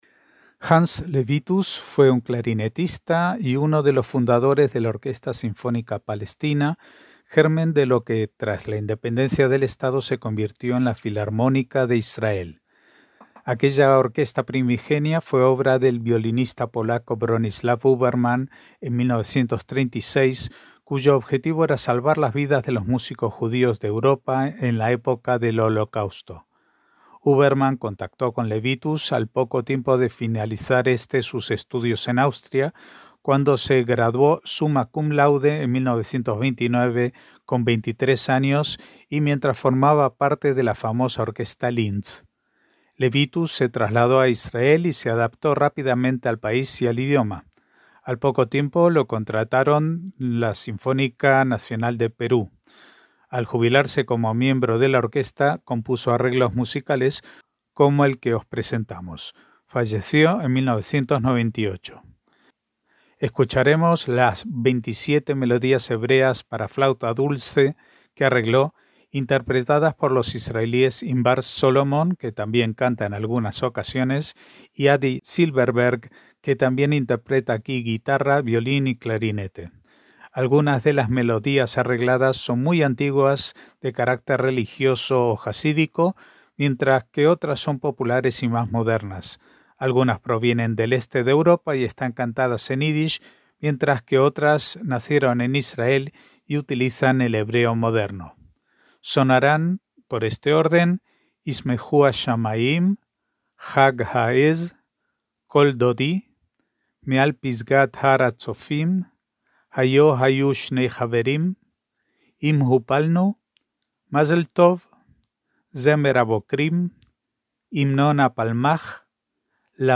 27 melodías hebreas arregladas para flauta dulce por Hans Lewitus
MÚSICA CLÁSICA - Hans Lewitus fue un clarinetista y uno de los fundadores de la Orquesta Sinfónica Palestina, germen de lo que, tras la independencia del estado, se convirtió en la Filarmónica de Israel.